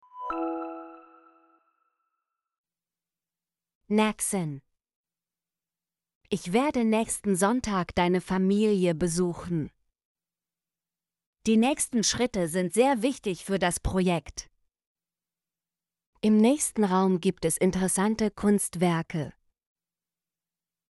nächsten - Example Sentences & Pronunciation, German Frequency List